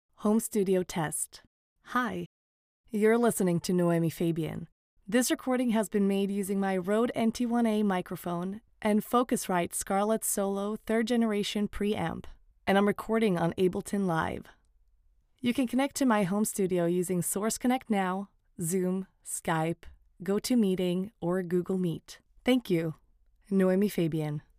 Scandinavian, Swedish, Female, Studio, 20s-40s